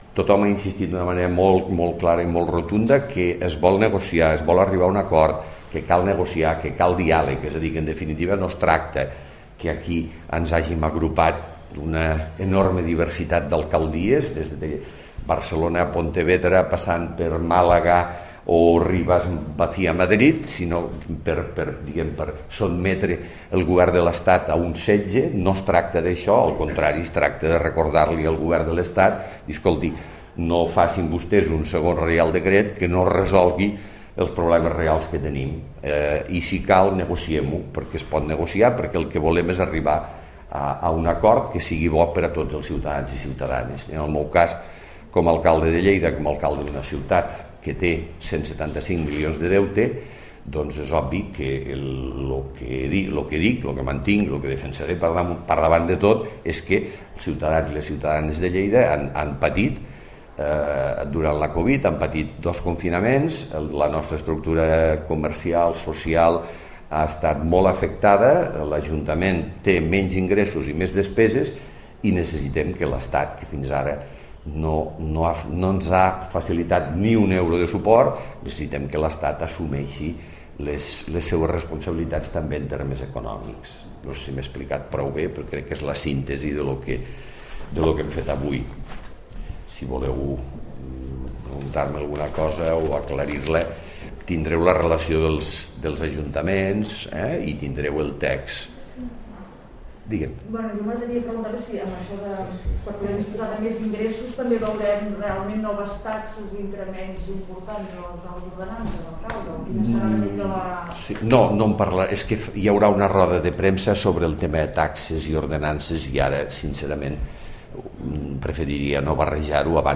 Tall de veu alcalde de Lleida Miquel Pueyo sobre la reunió amb alcaldes de 29 municipis de tot l'estat sobre els romanents.